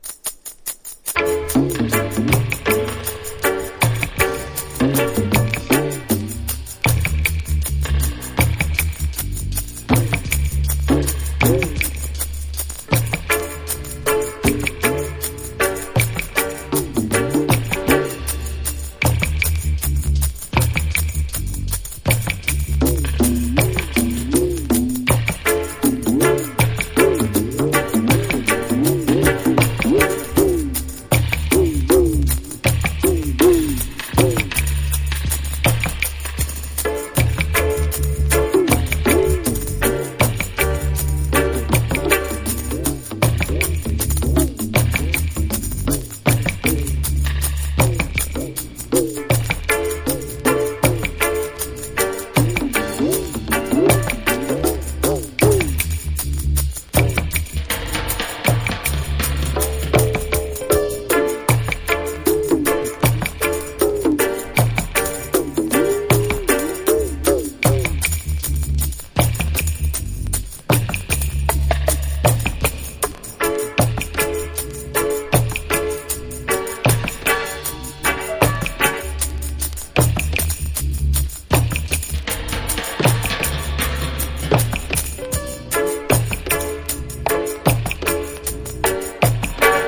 • REGGAE-SKA
ヘヴィーでキラーなダブ満載で最高！
# ROOTS# DUB / UK DUB / NEW ROOTS